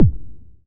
Index of /90_sSampleCDs/Club_Techno/Percussion/Kick
Kick_21.wav